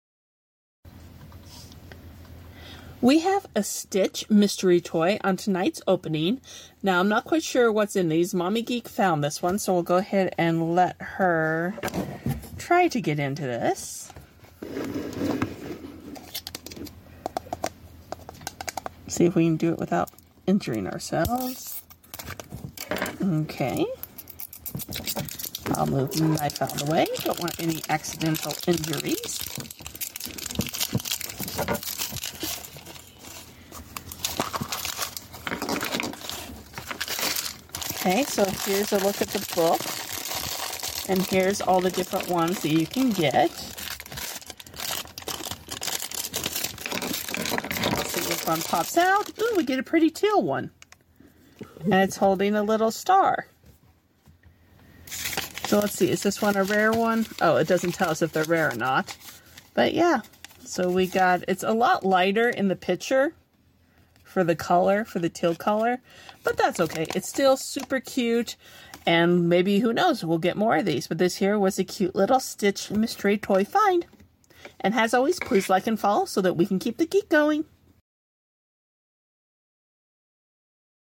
Stitch mystery capsule toy opening.